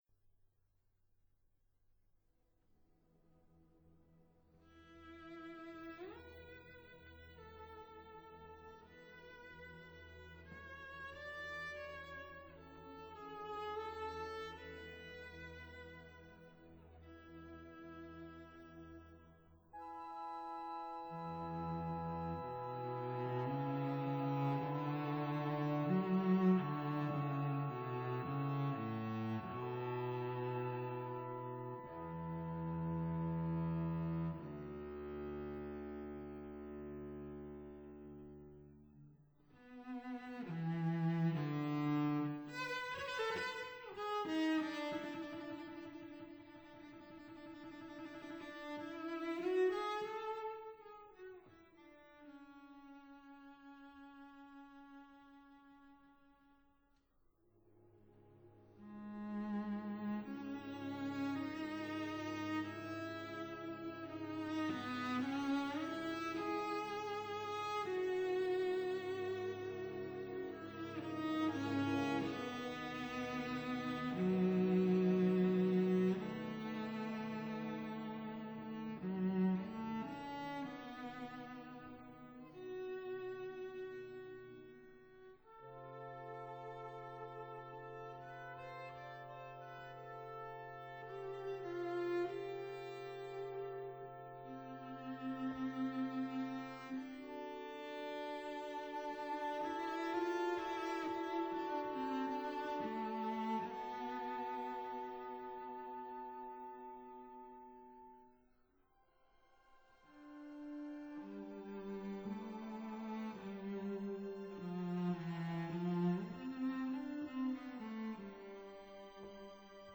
for cello & orchestra